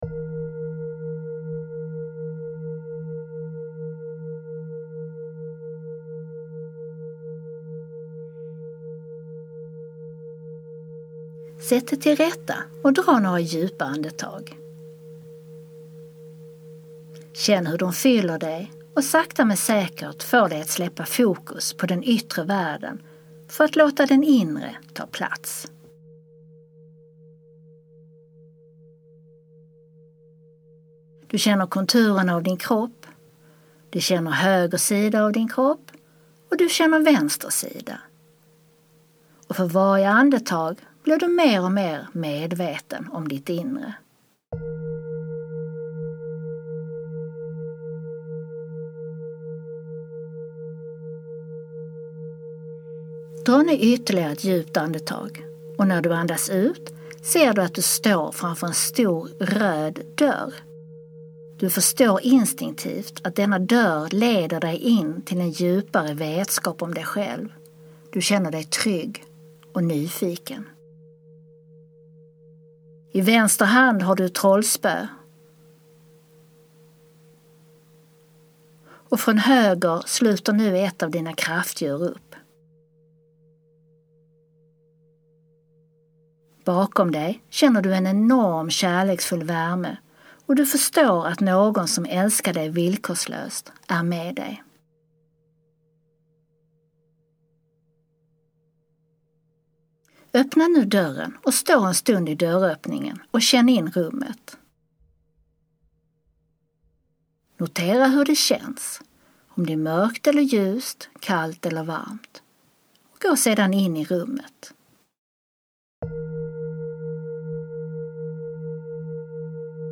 Denna meditation är som en healingsession som du gör tillsammans med dig själv och dina guider - och all den helande energin.